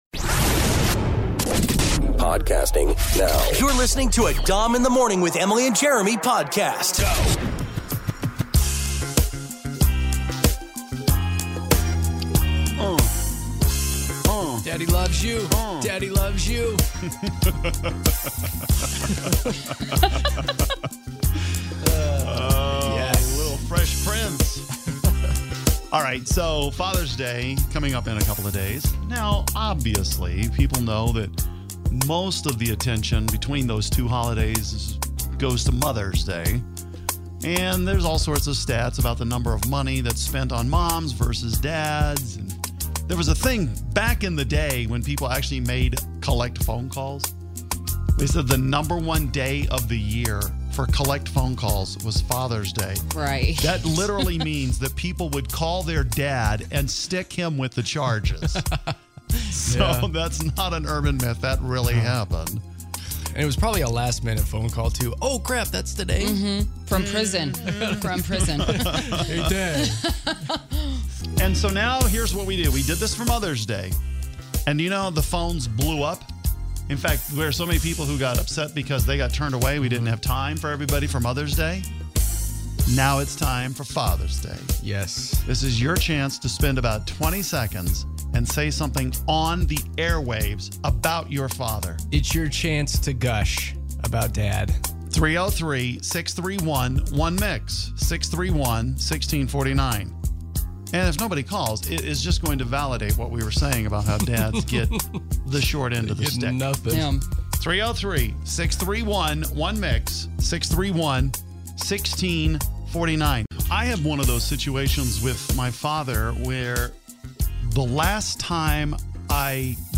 Some of the best dad calls you'll ever hear! Happy Fathers Day from Mix.